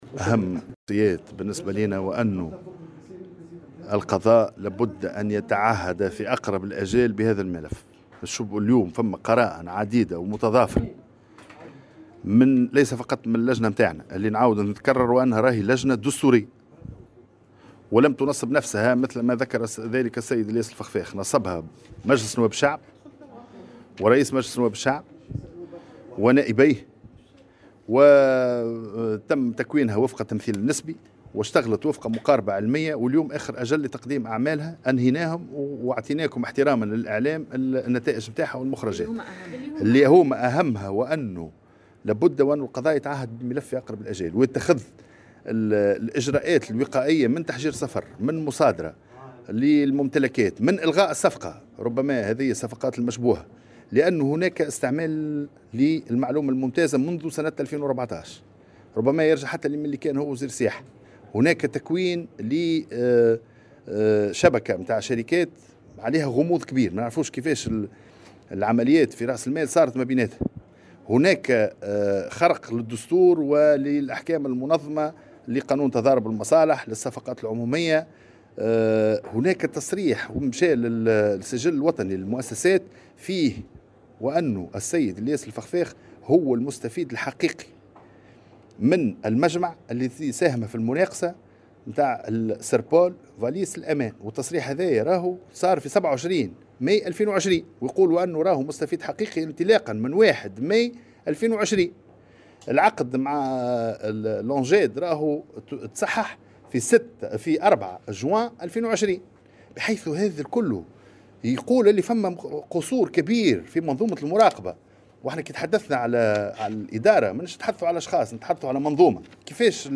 وأضاف اللومي خلال ندوة صحفية اليوم أنه لابد من إحالة الملف على القضاء في أقرب الآجال و ضرورة اتخاذ الإجراءات الوقائية من منع للسفر و مصادرة أملاك المورطين في هذا الملف وإلغاء الصفقات المشبوهة.